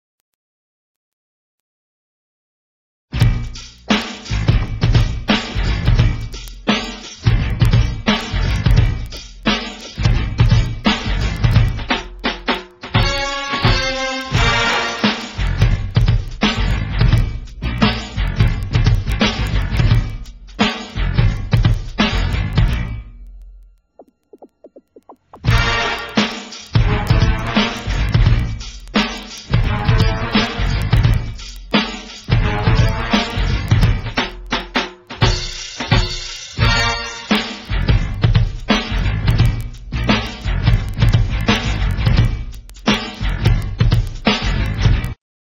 HIPHOP, RAP KARAOKE CDs